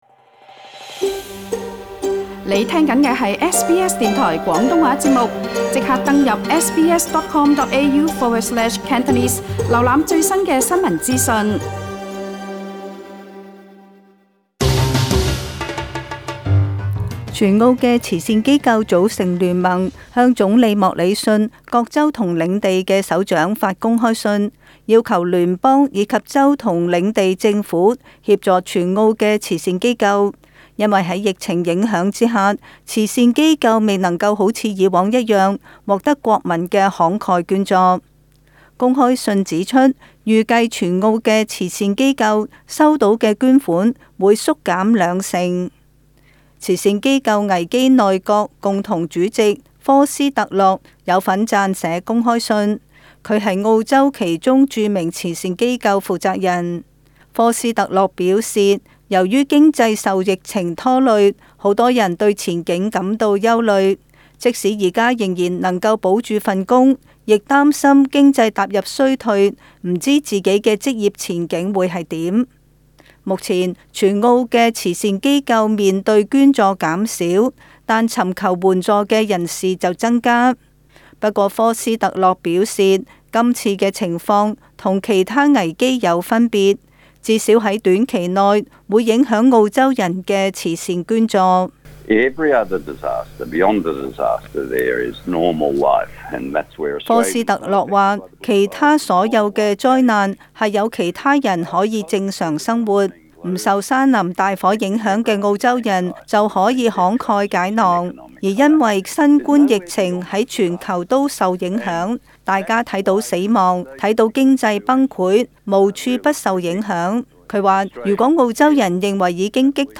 【时事报导】